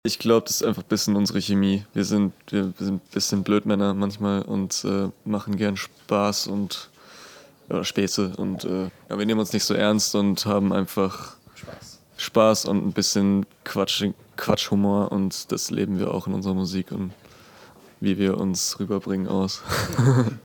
O_Ton_2.mp3